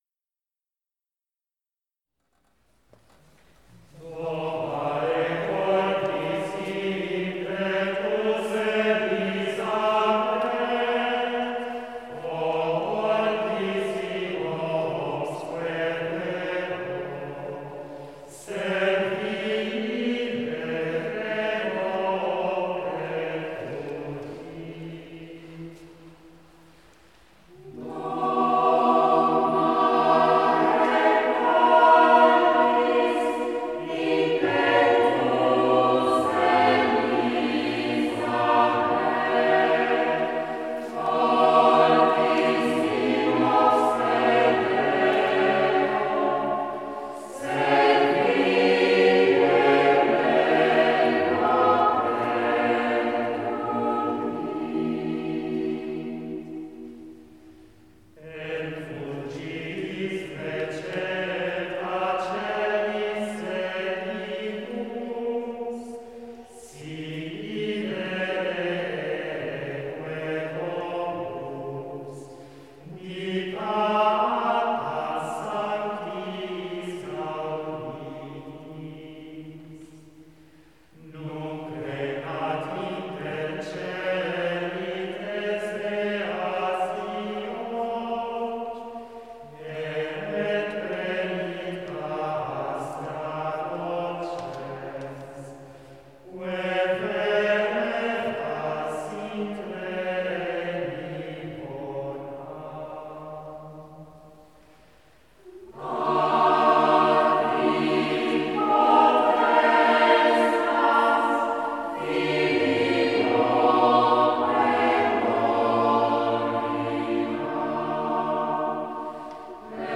The gregorian chant
Being one of the most ancient musical traditions of the Western World, gregorian chant constitutes a form of sung prayer.